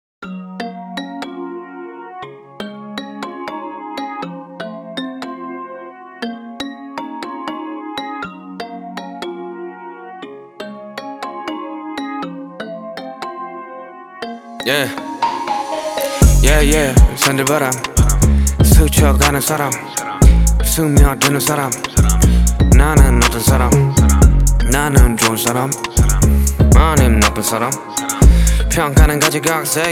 Genre: K-Pop